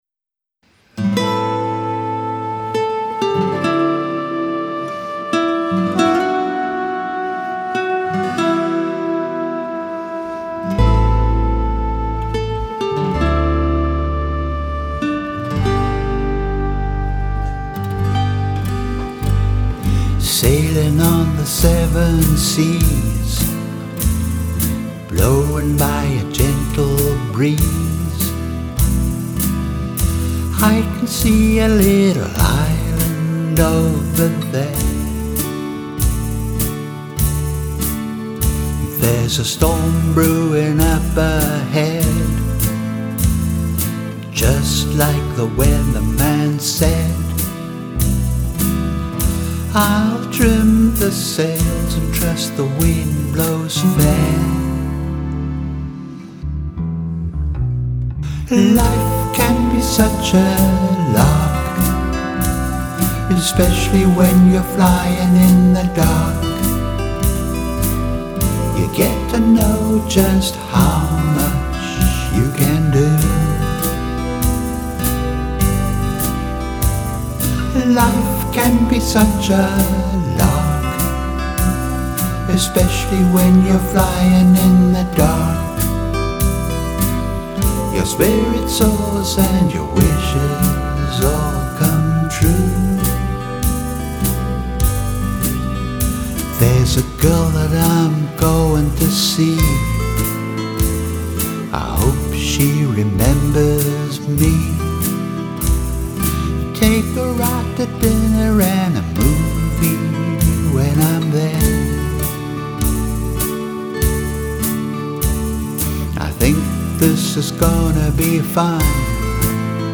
The drums come out of a Boss drum box (not programmed!).
I wrote all the songs and play and sing everything.